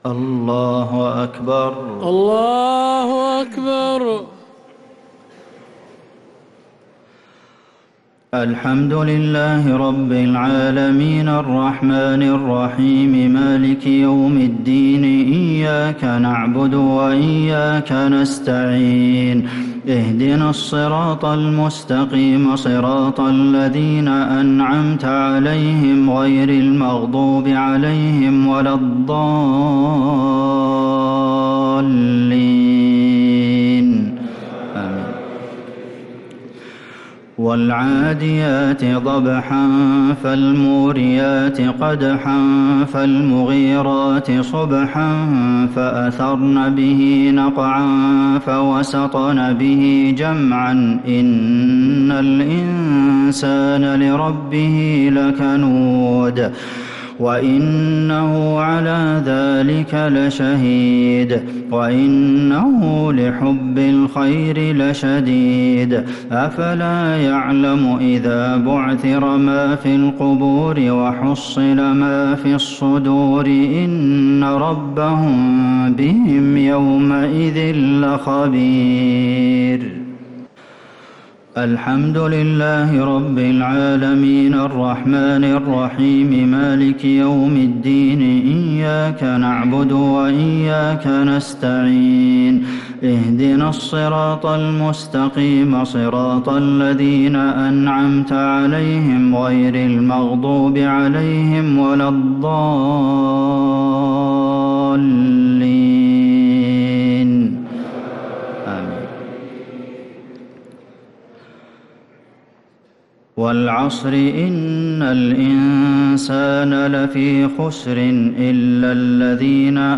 الشفع و الوتر ليلة 16 رمضان 1446هـ | Witr 16th night Ramadan 1446H > تراويح الحرم النبوي عام 1446 🕌 > التراويح - تلاوات الحرمين